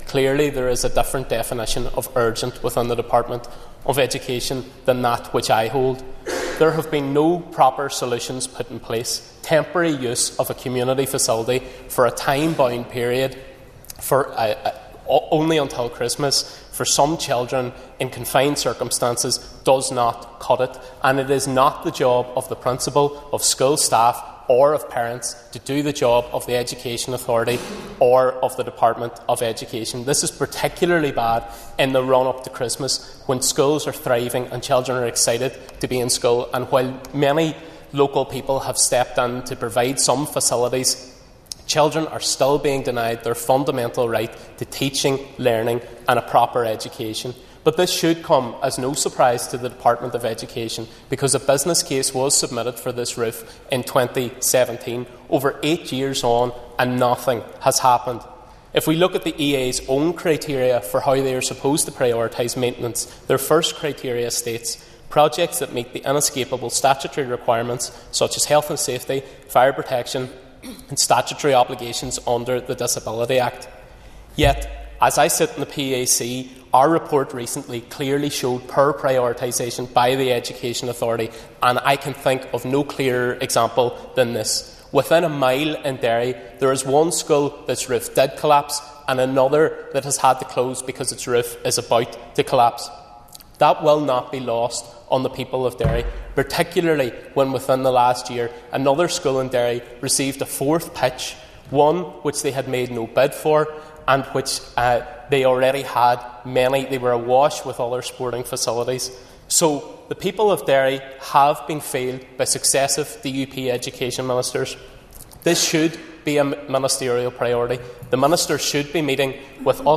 The future of Nazareth House Primary School in Derry has again been raised in the Assembly, with Foyle MLA Padraig Delargy saying successive DUP ministers have failed the school.